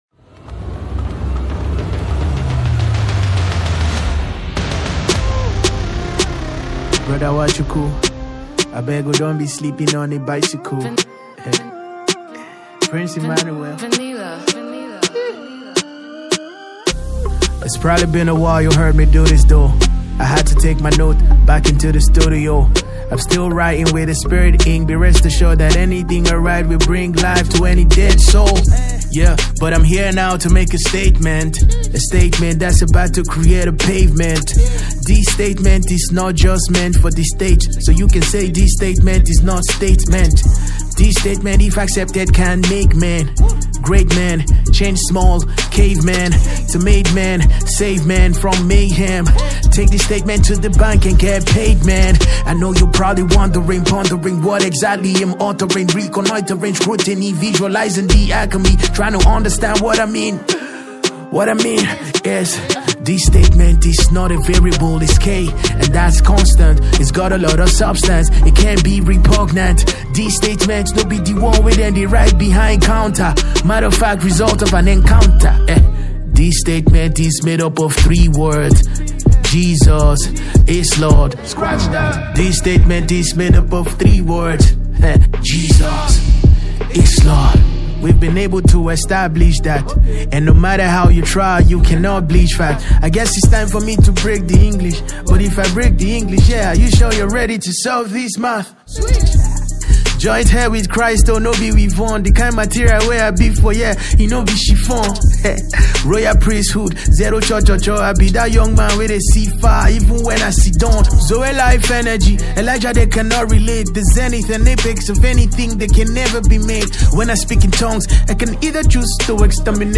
no chorus only rap verses